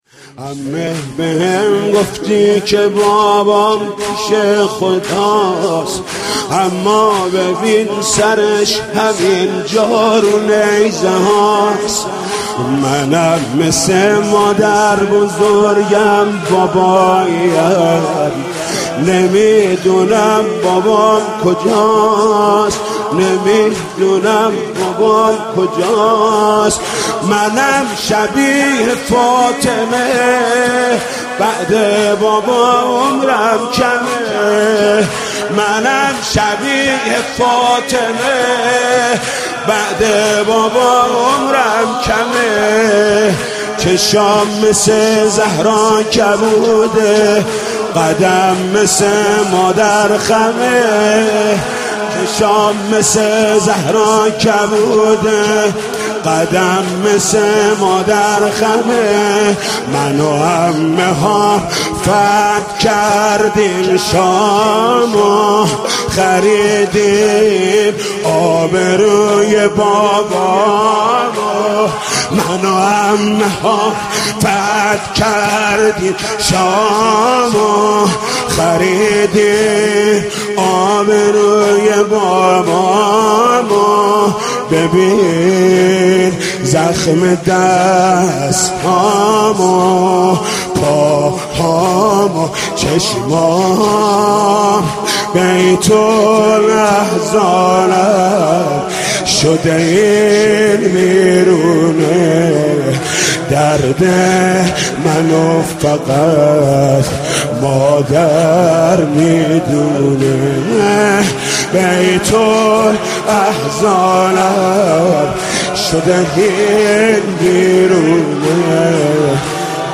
مداحی سوزناك محمود كریمی در شب سوم محرم را بشنوید